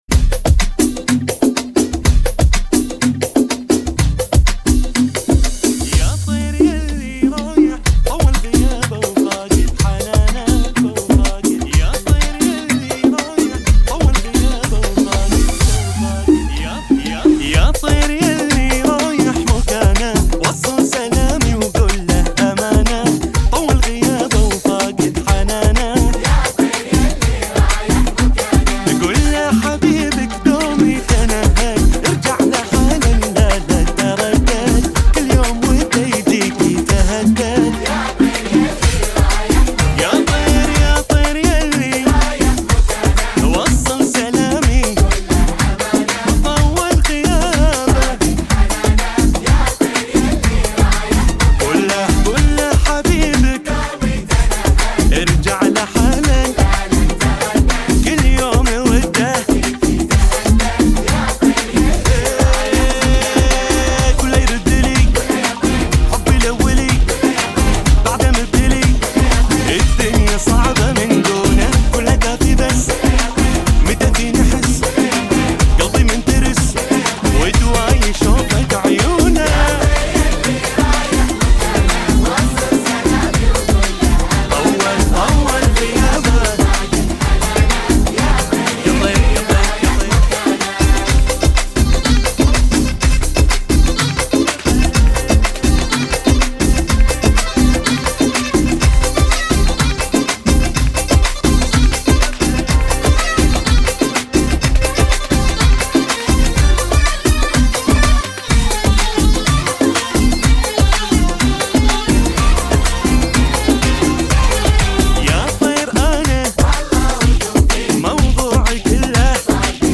124 bpm
بدون جنقل